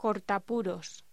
Locución: Cortapuros